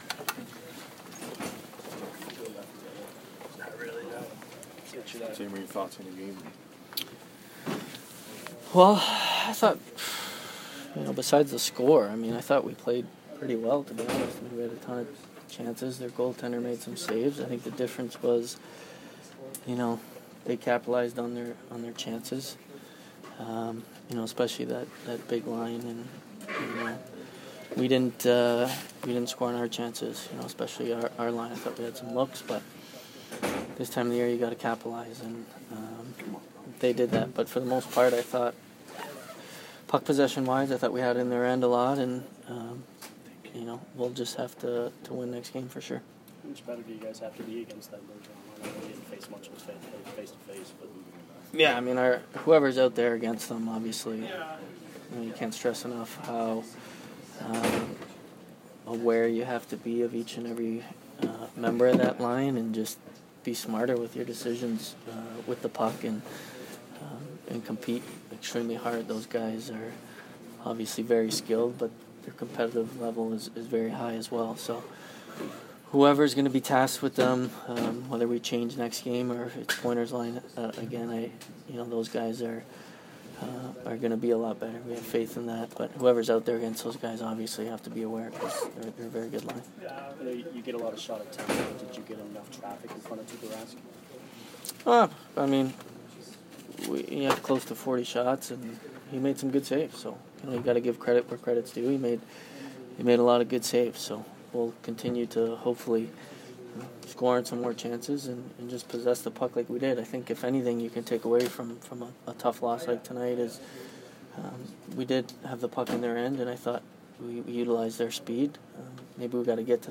Steven Stamkos post-game 4/28